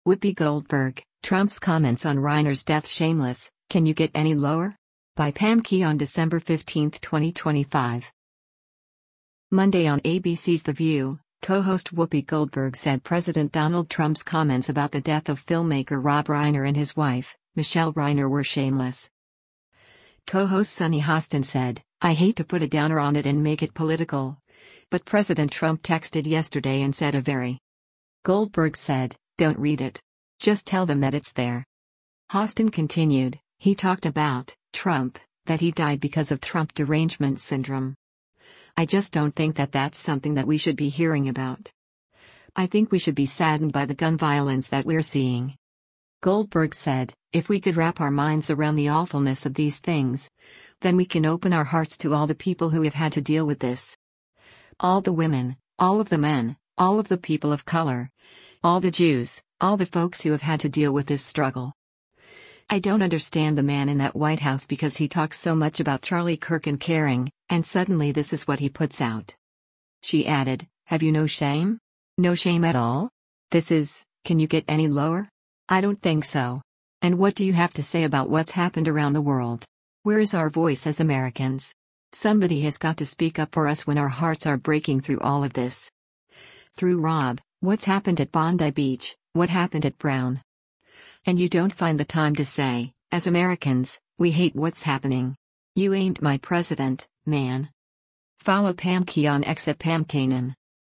Monday on ABC’s “The View,” co-host Whoopi Goldberg said President Donald Trump’s comment’s about the death of filmmaker Rob Reiner and his wife, Michele Reiner were shameless.